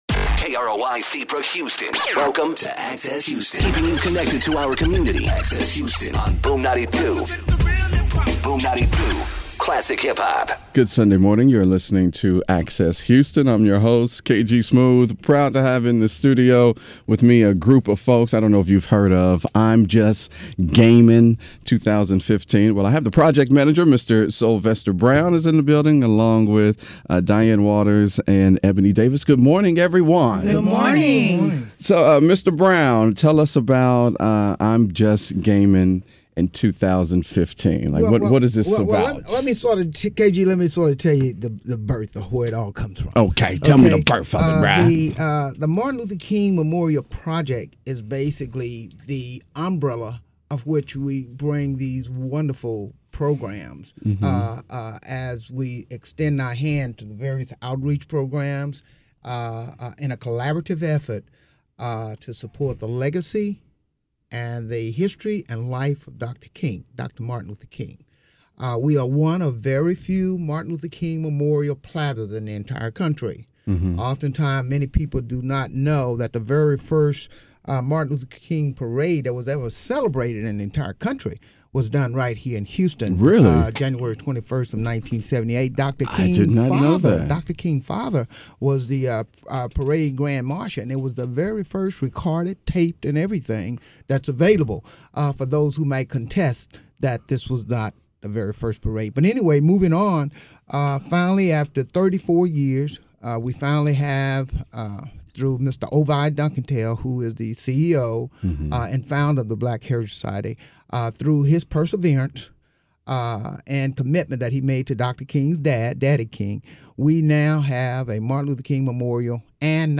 This interview is very informative and something that the youth can be apart.